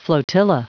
Prononciation du mot flotilla en anglais (fichier audio)
Prononciation du mot : flotilla